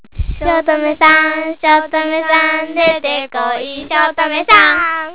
ためになる広島の方言辞典 さ．